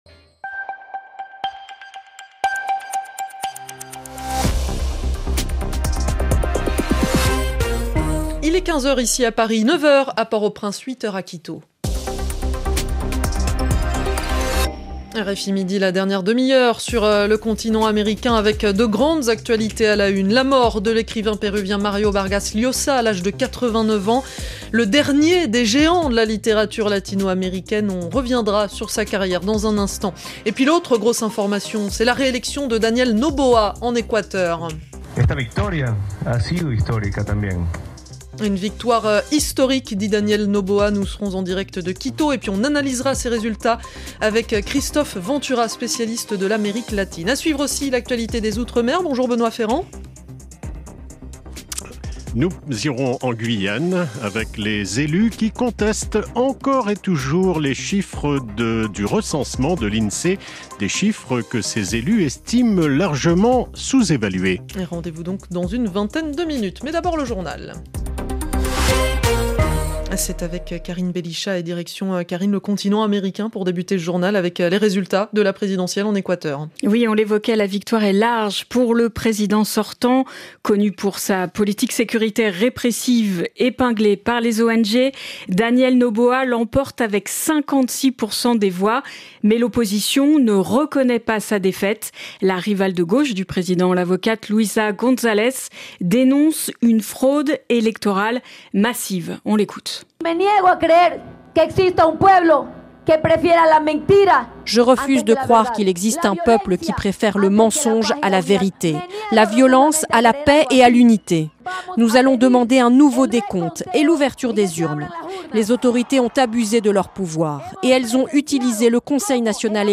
C’est pour cet auditoire que, chaque jour, RFI consacre un reportage, ou une interview, spécifiquement consacré à Haïti.